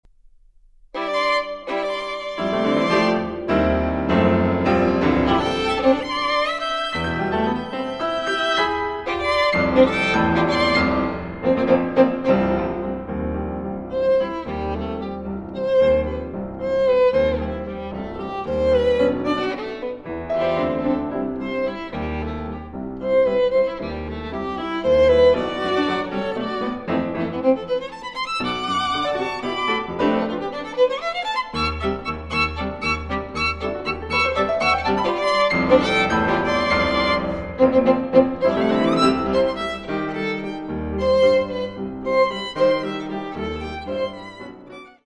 Sonata for Violin and Piano (1963) (22:57)